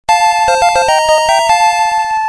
sfx_level_up.mp3